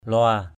lua.mp3